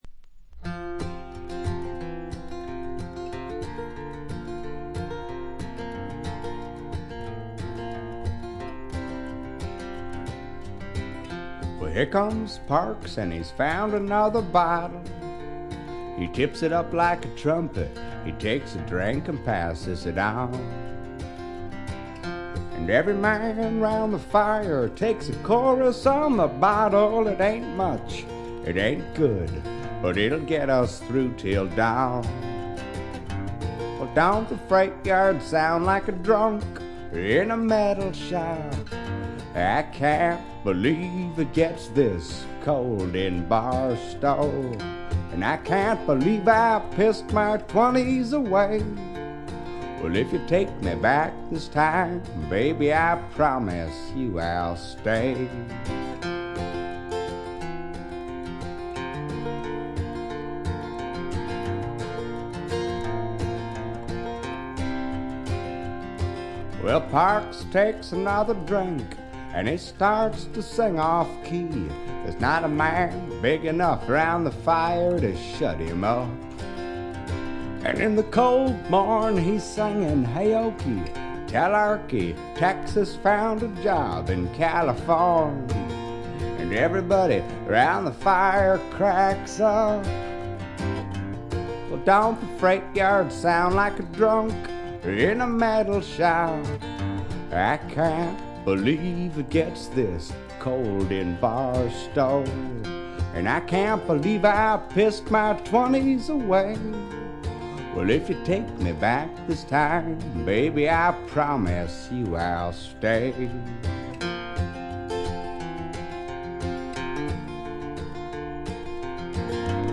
ごくわずかなノイズ感のみ。
84年デビューと後発組ながら70年代のフォーキー・シンガーソングライターそのままの世界を見せてくれる激渋な名盤です。
試聴曲は現品からの取り込み音源です。